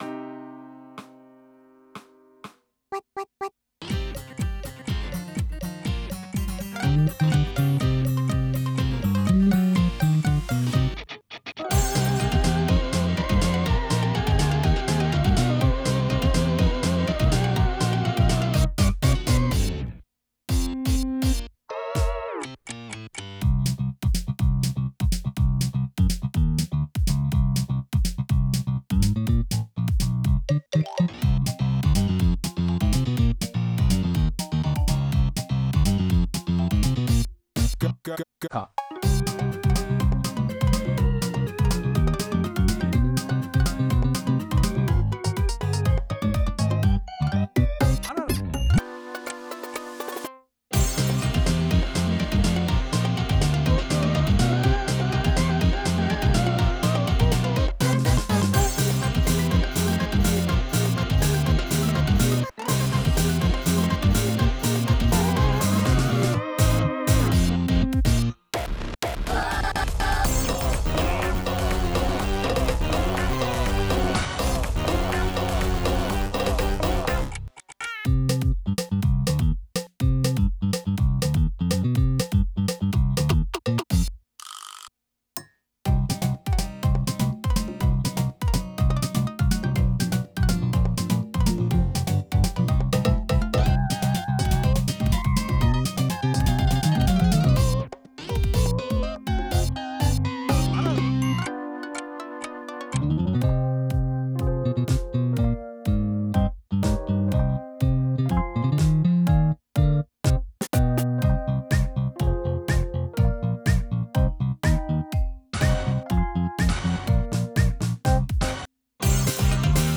inst（カラオケ）素材など
音源（BPMは123）